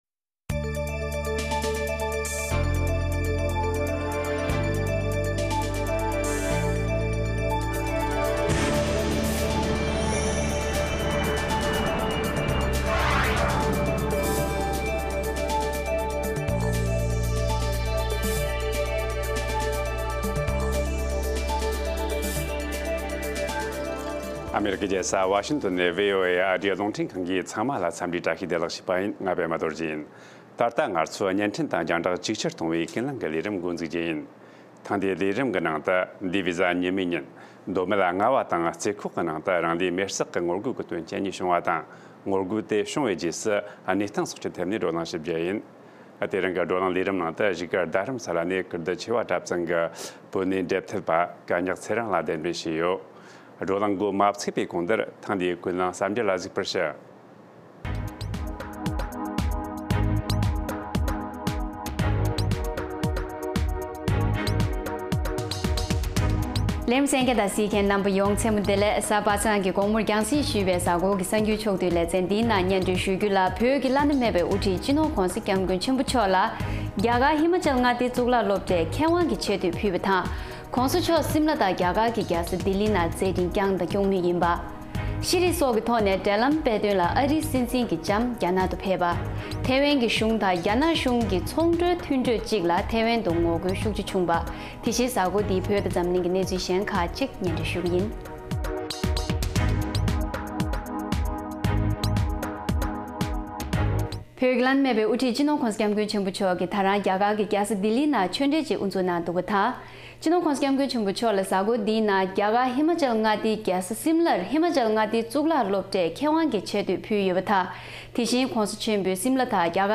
དགོང་དྲོའི་གསར་འགྱུར།